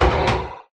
Sound / Minecraft / mob / irongolem / hit4.ogg
hit4.ogg